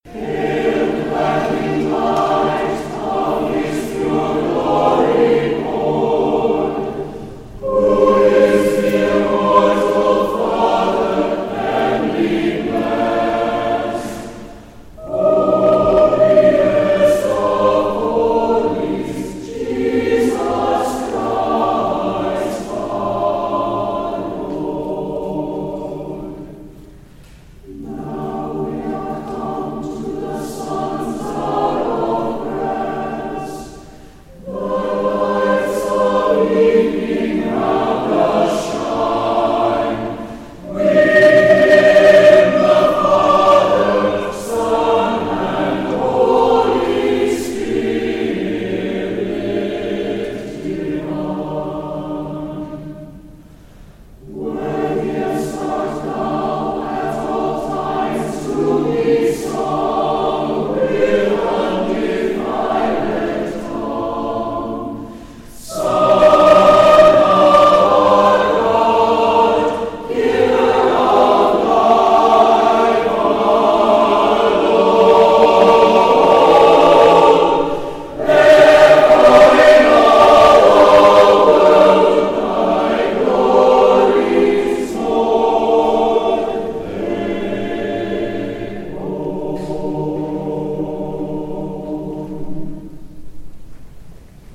from St. Mark’s 10th Anniversary Evensong